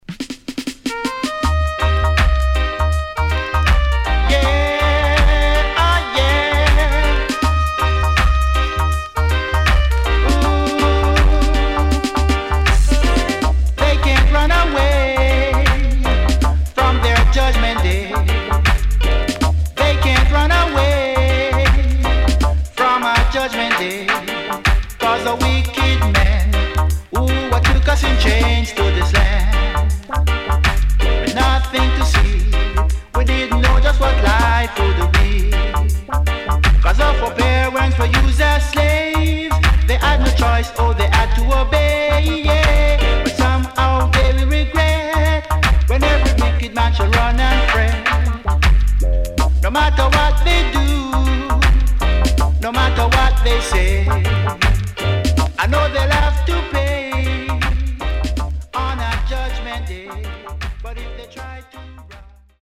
HOME > Back Order [DANCEHALL DISCO45]
SIDE A:所々チリノイズ入ります。